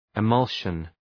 Προφορά
{ı’mʌlʃən}